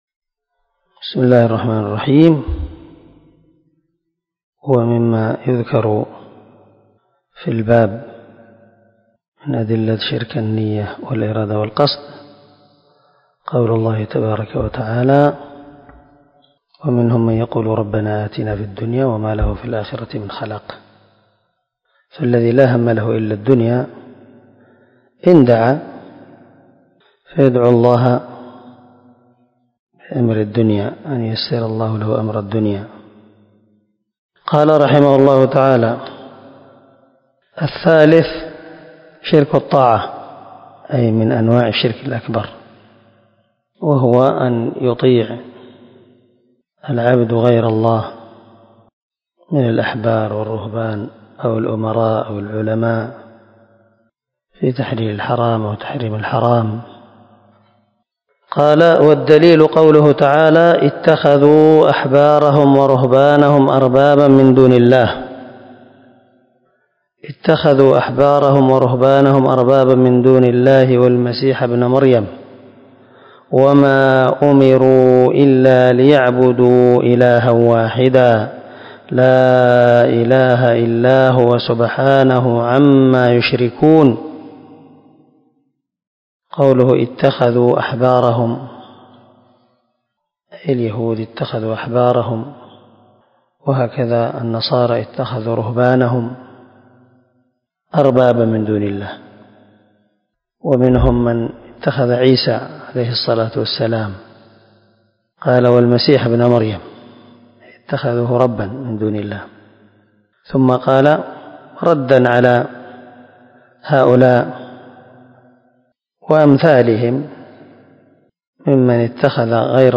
🔊الدرس 27 تابع لأنواع الشرك الأكبر